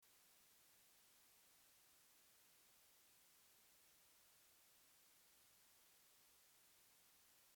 Immer wenn ich versuche aufzunehmen hab ich ein starkes rauschen im hintergrund.
kein problem diese datei wurde ohne angeschlossenes mikrofon gemacht.